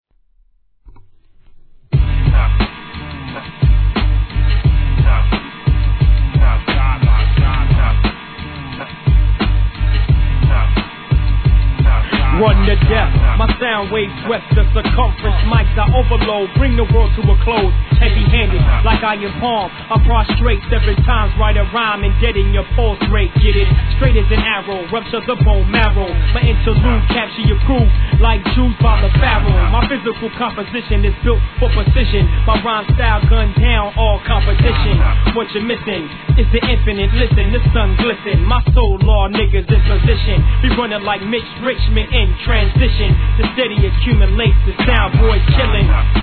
HIP HOP/R&B
タイトル通り激DOPEに突っ走るプロダクションは生半可な気持ちで足を踏み入れるとぼっとばされます!!!